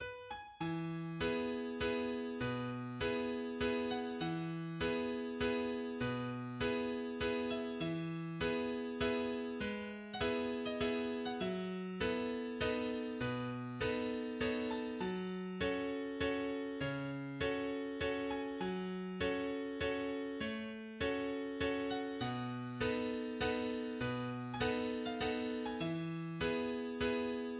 Dynamiden op. 173 is a Viennese Waltz composed by Josef Strauss in 1865.